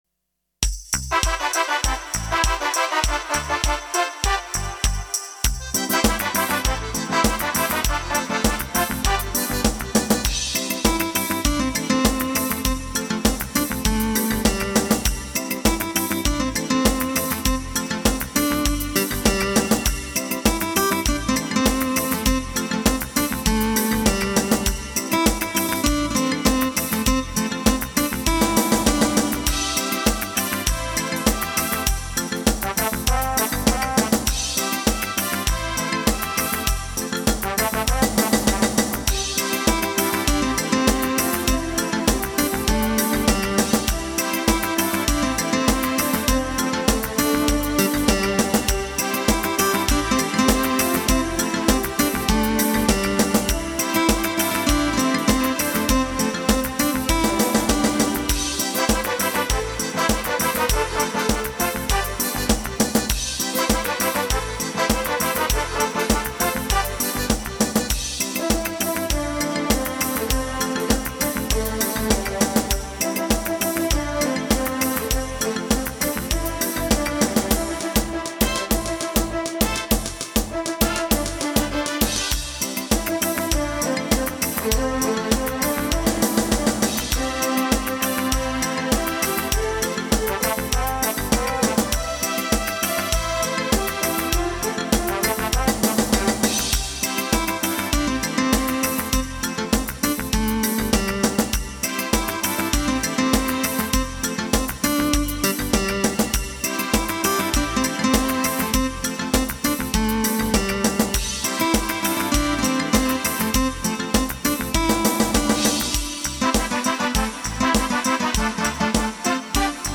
50 T/M